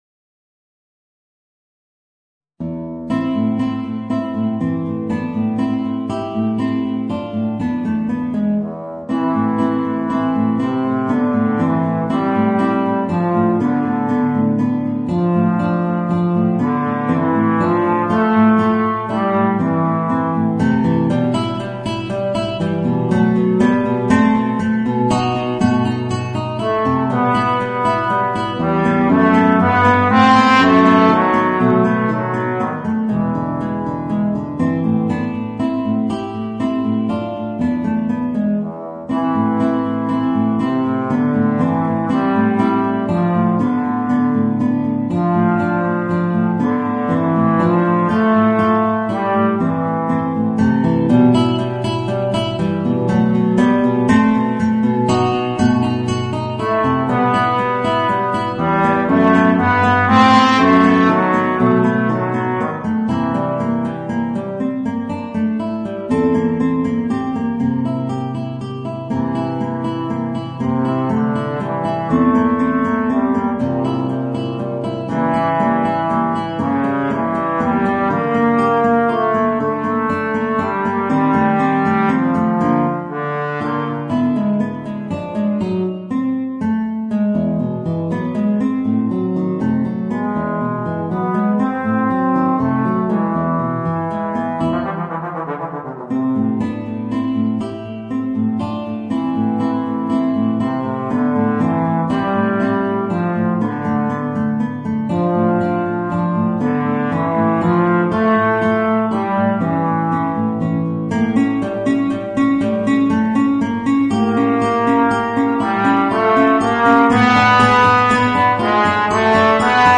Trombone basse & guitare